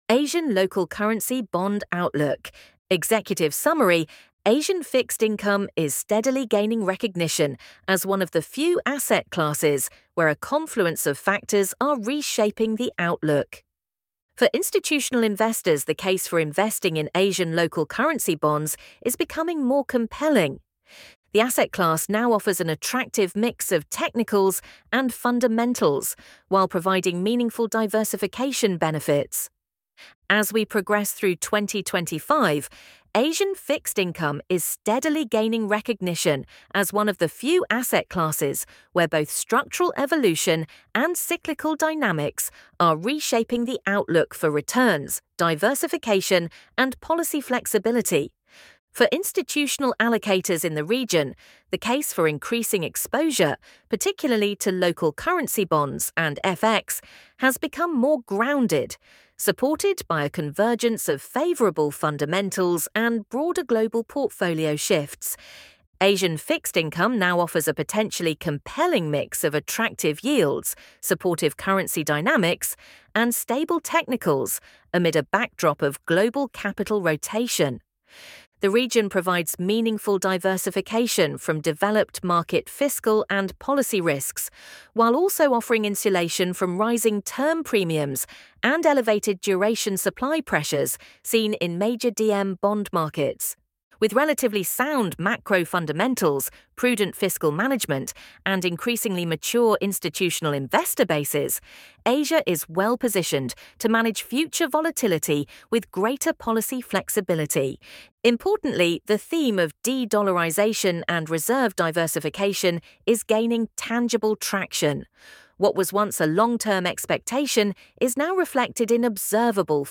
Asian_Local_Currency_Bond_Outlook_voiceover.mp3